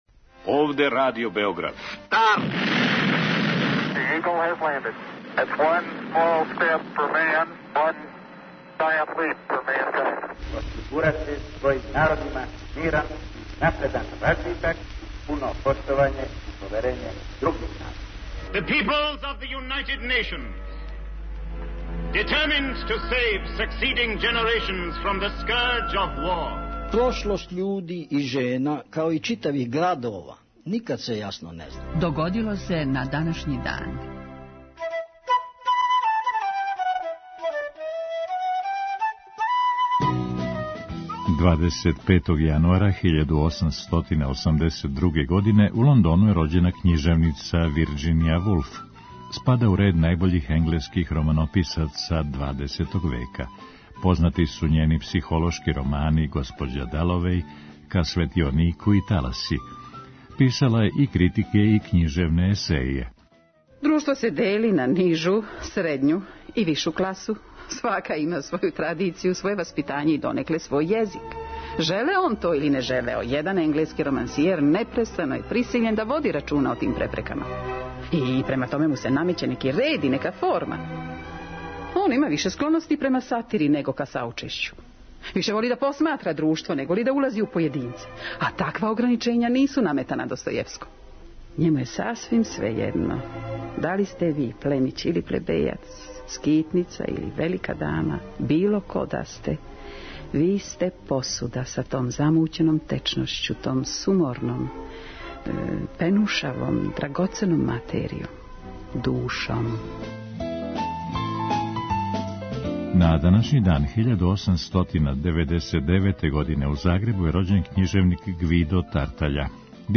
У петотоминутном прегледу, враћамо се у прошлост и слушамо гласове људи из других епоха.